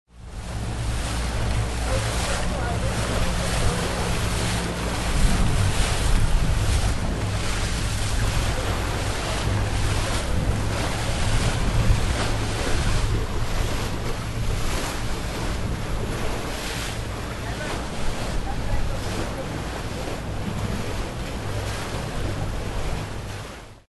Лодка скользит по волнам с пассажирами